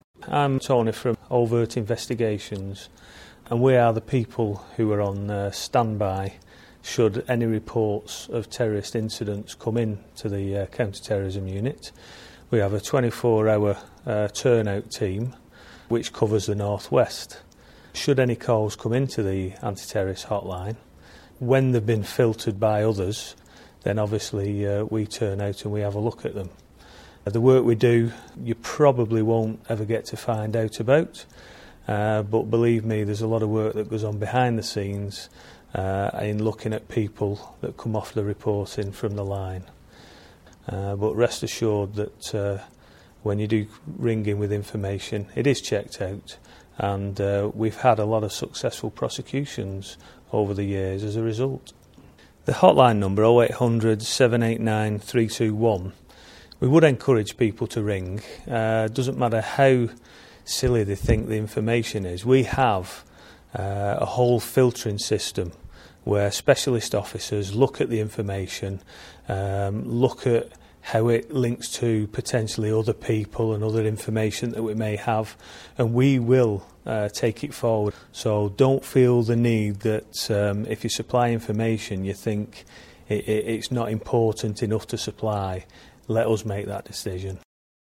A counter-terrorism investigator talks about his role and the Anti-Terrorism hotline